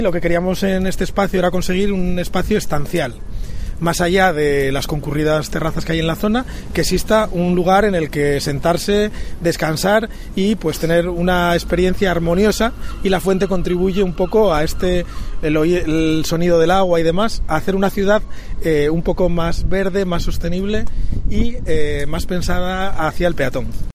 Declaraciones de Pelayo García, concejal de obras y movilidad